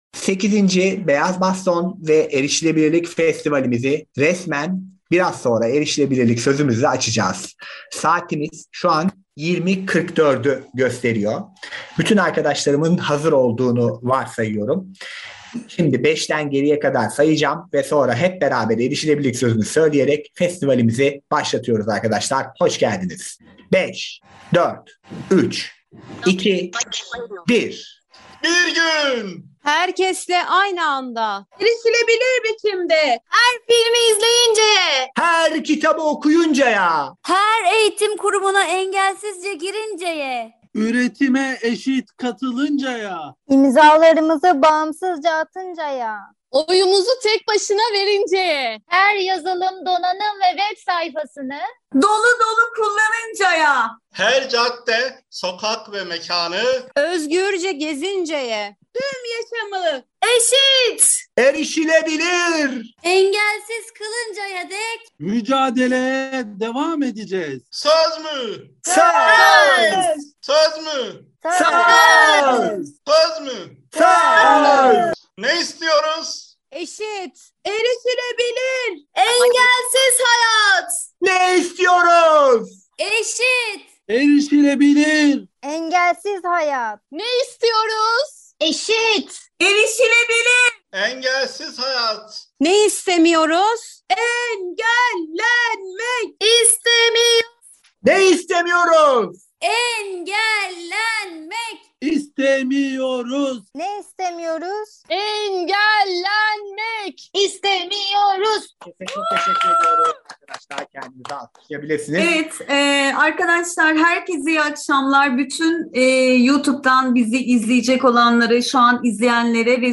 Sağlamcılığa inat, farklılıklarını yaşat mottosuyla düzenlenen 8. Beyaz Baston ve Erişilebilirlik Festivali EEEH Dergimizin ev sahipliğinde güzel bir seminer ile başladı.